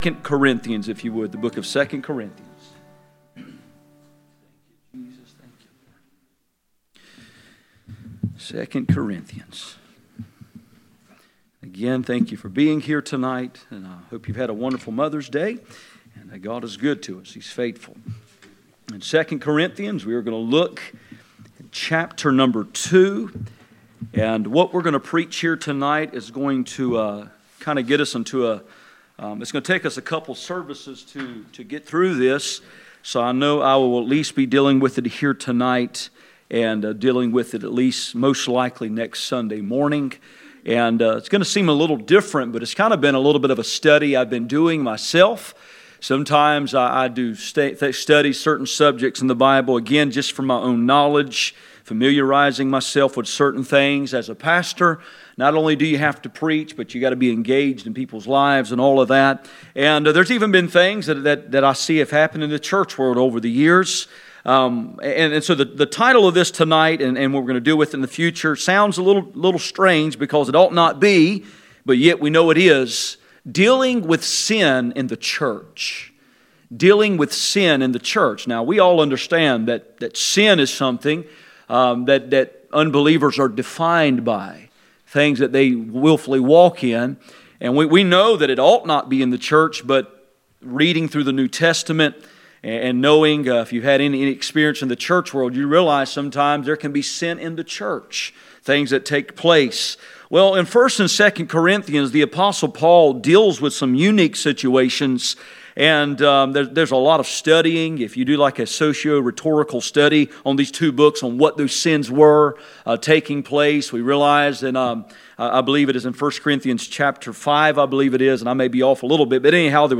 Passage: 2 Corinthians 2:5-11 Service Type: Sunday Evening